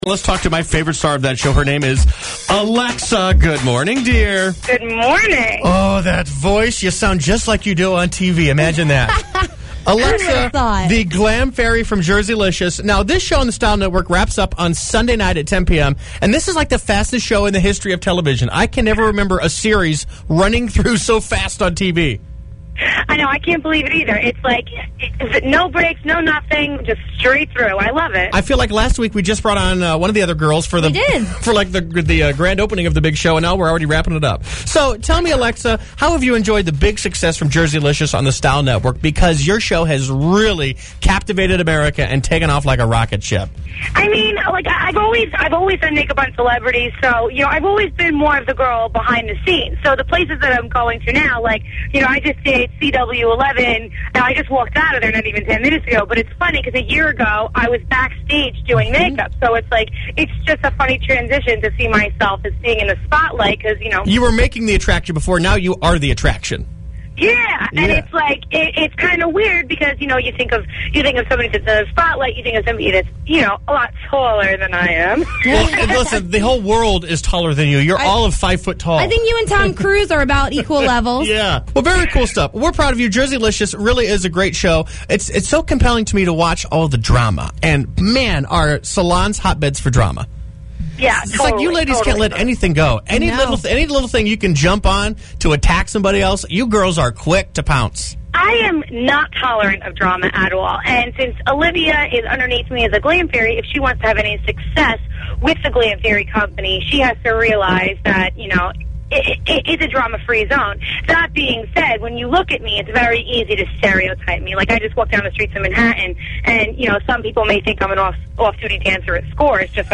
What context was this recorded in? Interview with North Carolina’s #1 radio station Z107.5